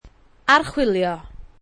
Listen to the pronuncation of Archwilio
Archwilio [Ar-ch-oo-il-ee-o] vb, to explore, examine, audit